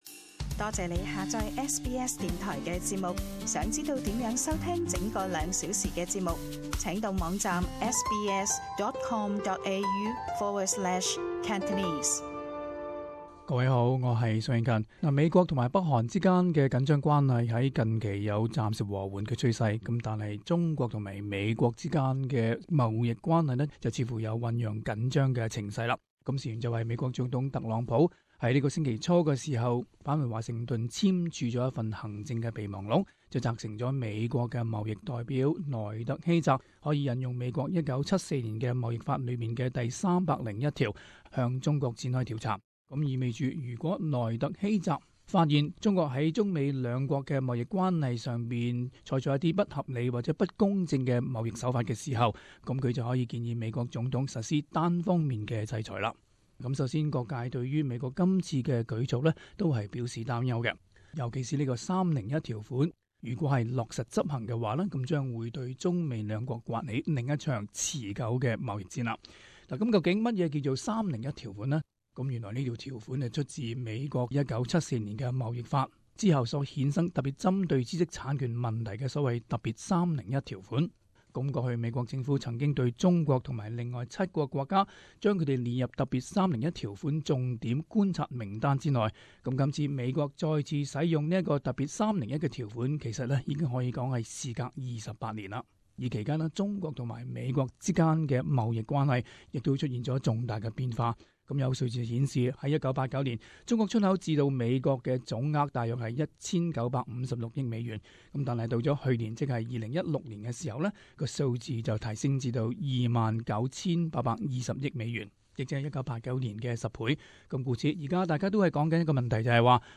【时事报导】各界关注中美贸易紧张关系骤现